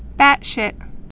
(bătshĭt)